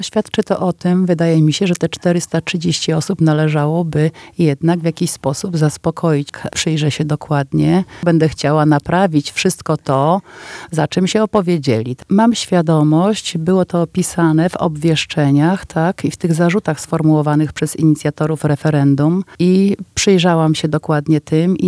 Choć referendum, ze względu na zbyt małą frekwencję uznano za nieważne, to mam świadomość, że nie wszyscy są z mojej pracy zadowoleni – mówiła na naszej antenie burmistrz Anna Gibas.